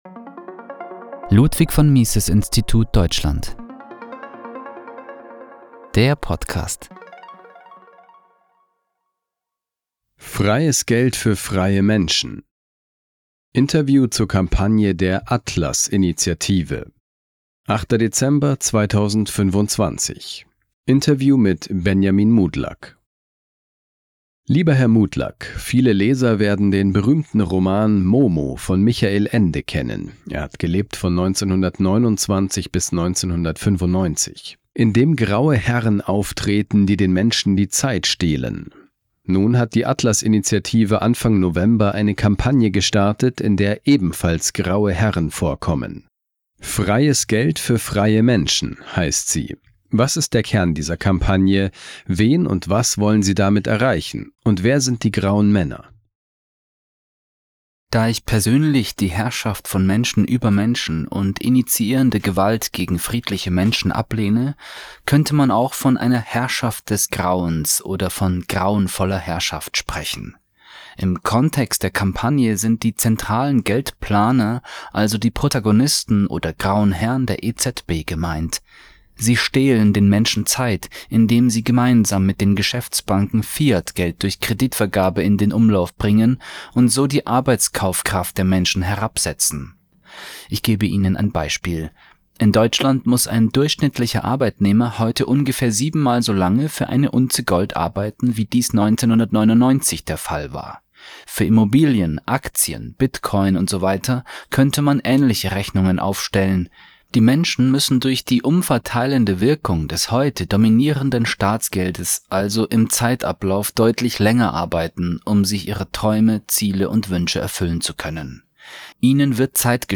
Interview zur Kampagne der „Atlas Initiative“